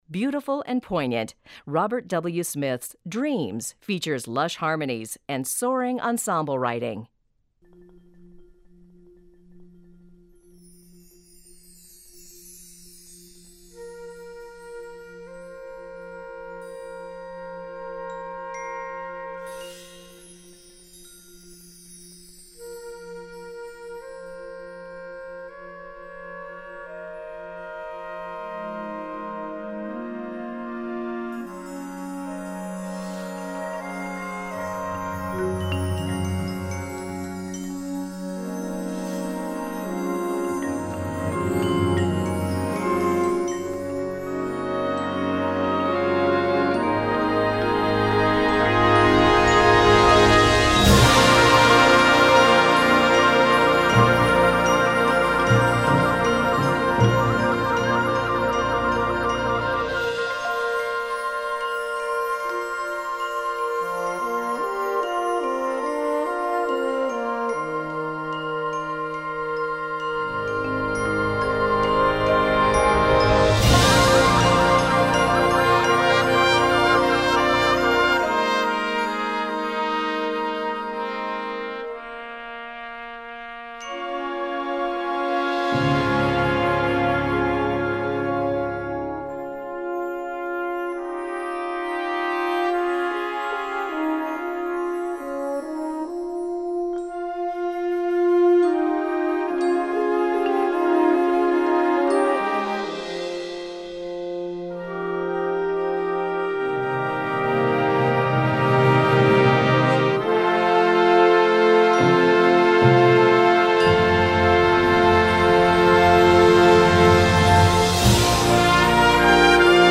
Besetzung: Blasorchester
lyrisches Werk